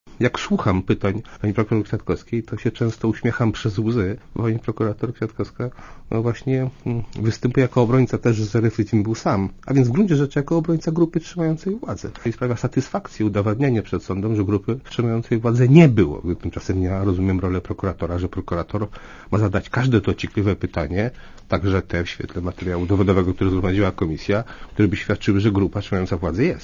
Mówi Tomasz Nałęcz